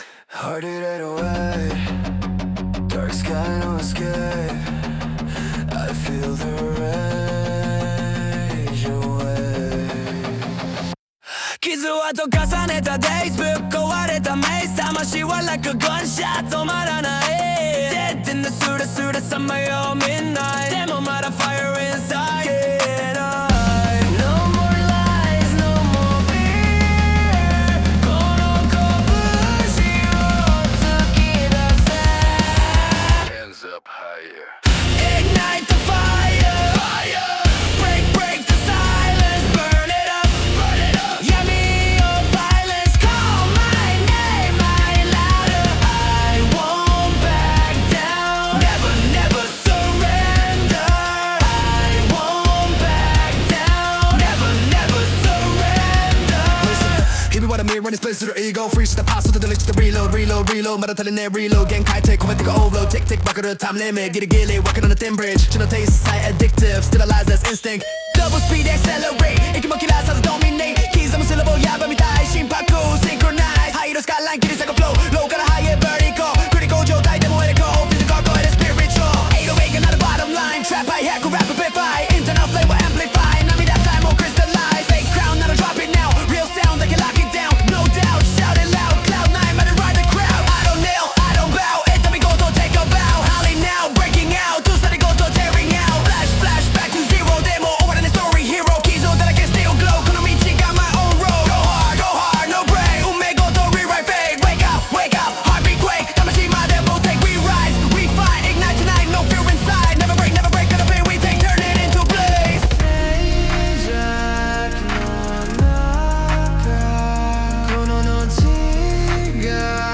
男性ボーカル
イメージ：ポストハードコア,男性ボーカル,メタル,RAP,ハード,エモロック,808サブベース